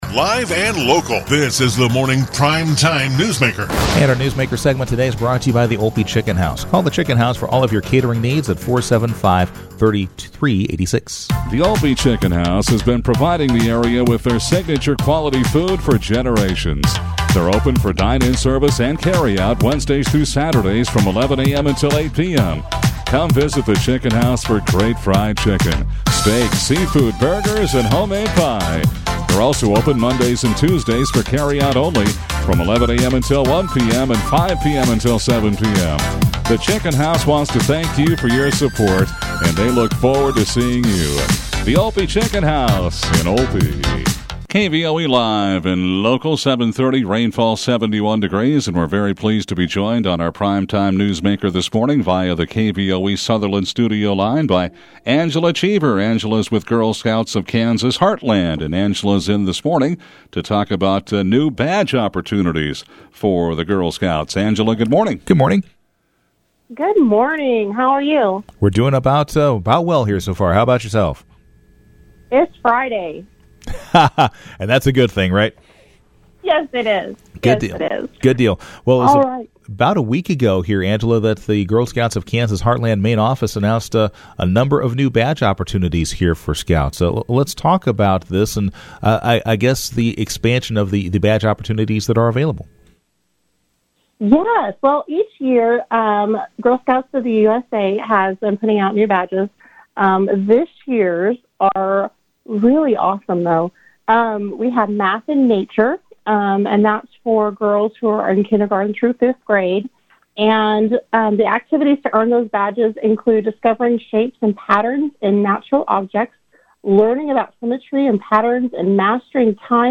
Newsmaker